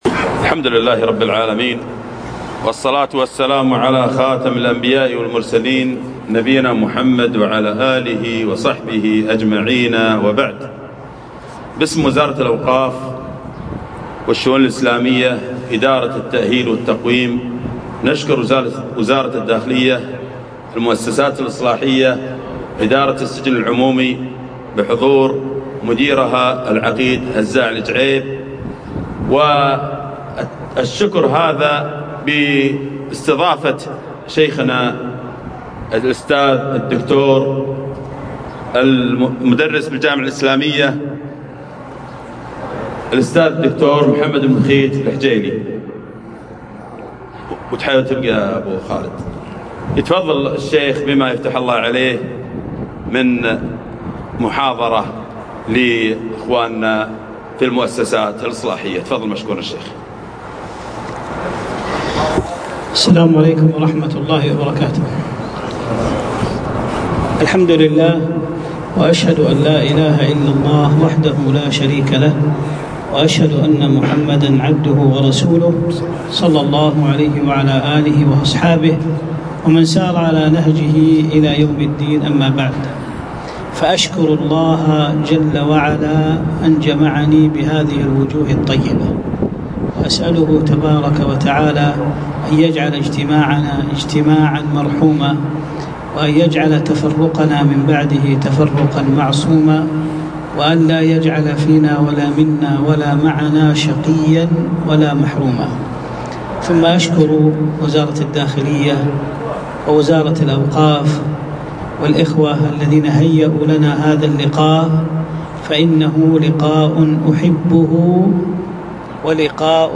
محاضرة - كيف أستقيم ؟